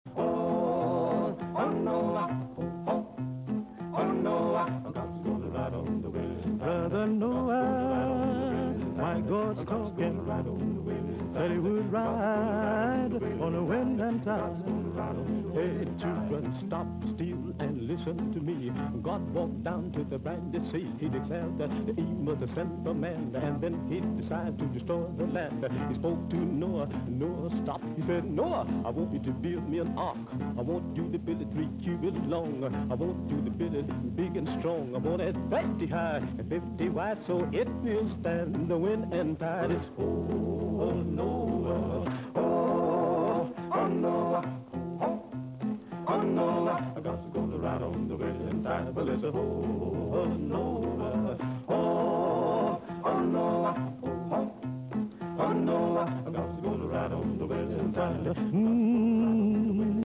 gospel quartet group